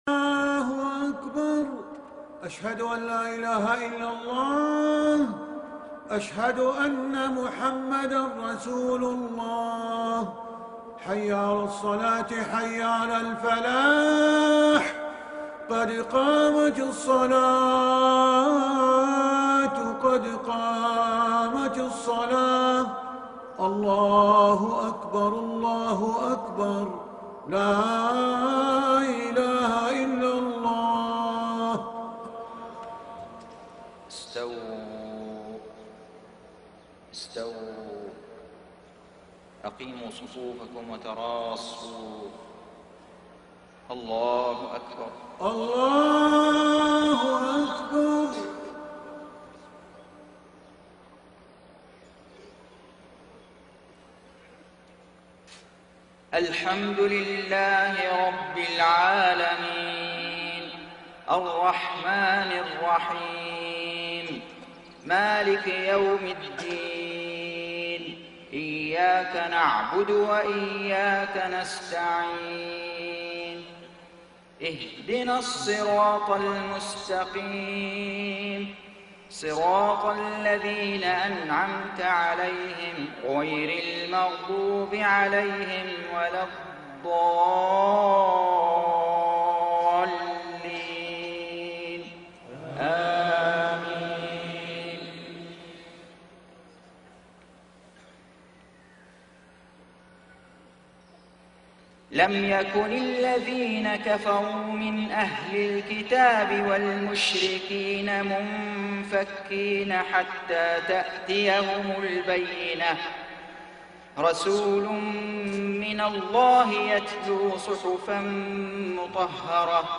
صلاة المغرب 6-1-1433هـ سورتي البينة و النصر > 1433 🕋 > الفروض - تلاوات الحرمين